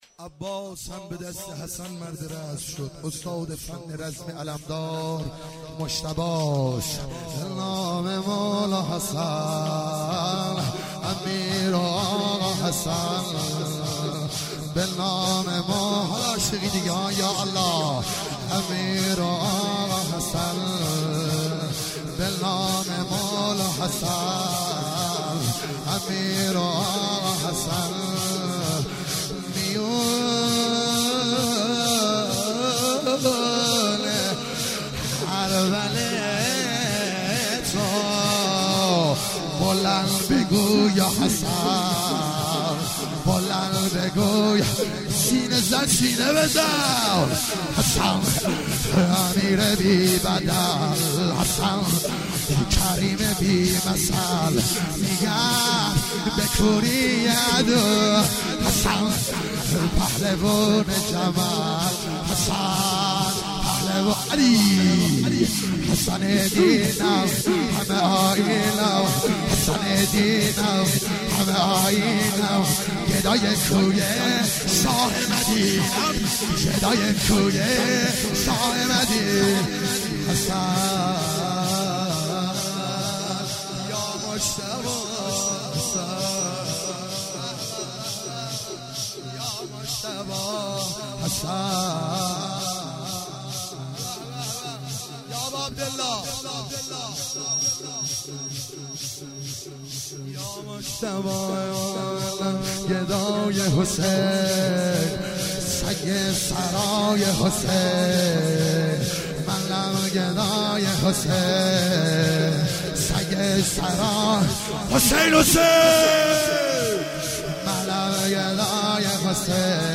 شور - به نام مولا حسن(ع)
توسل هفتگی